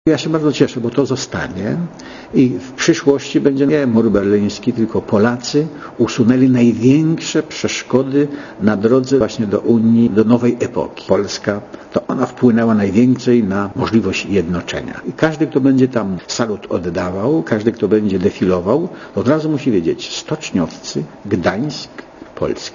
Dla radia Zet mówi Lech Wałęsa (88 KB)